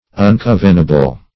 Search Result for " uncovenable" : The Collaborative International Dictionary of English v.0.48: Uncovenable \Un*cov"e*na*ble\, a. Not covenable; inconvenient.